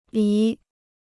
梨 (lí): pear.